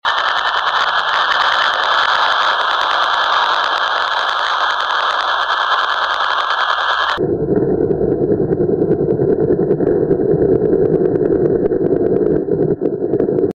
2 real pulsar sounds captured in irl